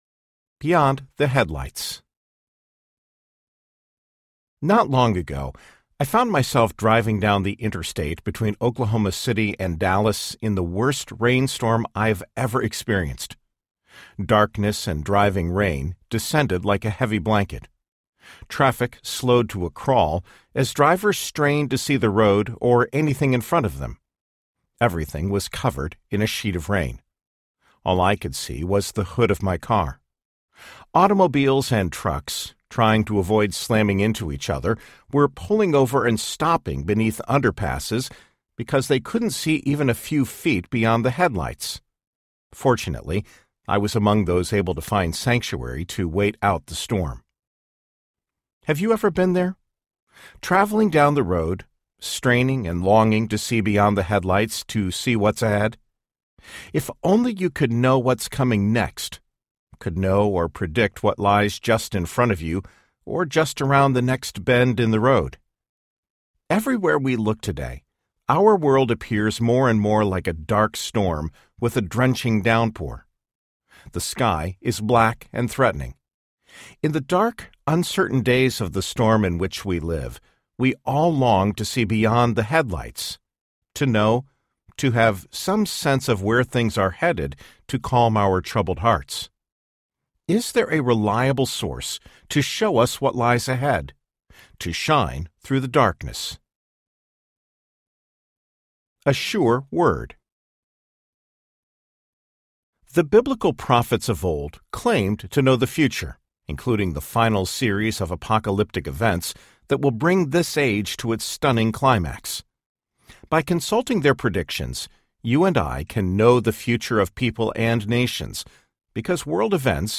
Showdown with Iran Audiobook
Narrator
4.6 Hrs. – Unabridged